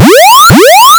ALARM_Arcade_Intense_loop_stereo.wav